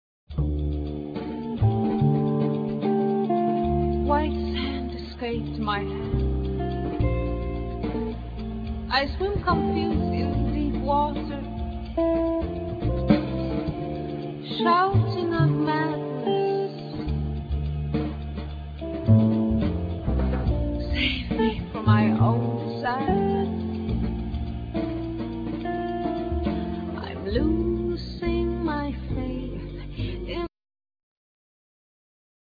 Guitar
Vocals
Soprano saxophone
Drums
Double Bass
Keyboards